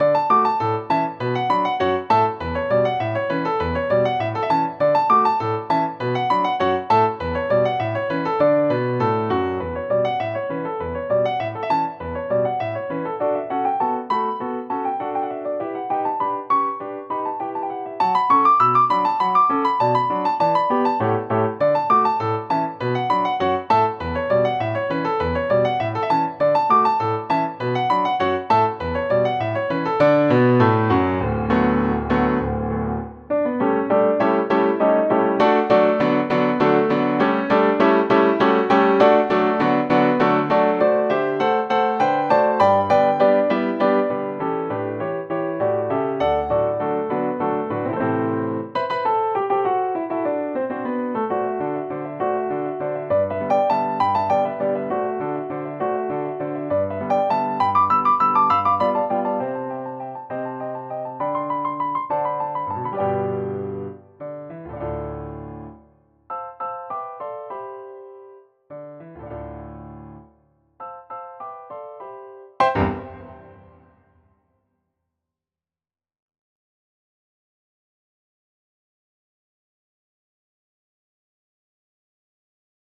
Piano solo
scherzo.wav